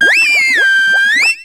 Catégorie:Cri Pokémon (Soleil et Lune) Catégorie:Cri d'Oratoria